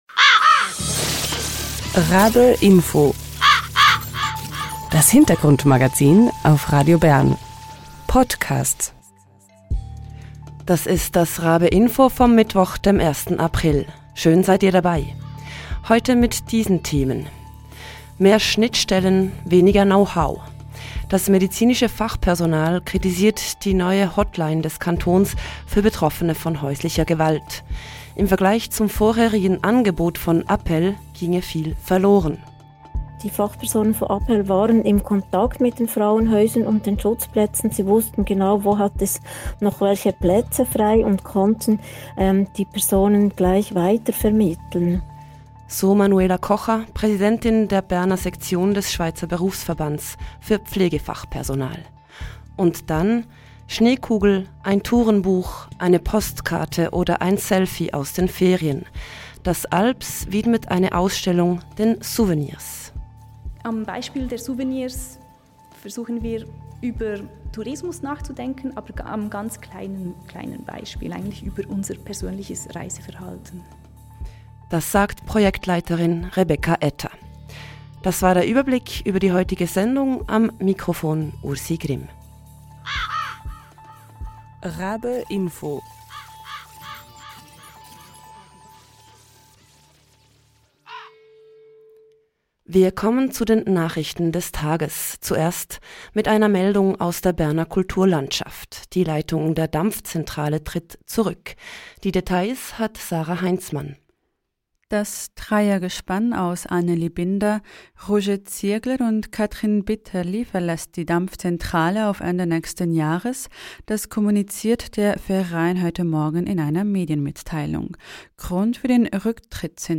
Im Interview spricht sie über die wichtigsten Kritikpunkte an der neuen Hotline.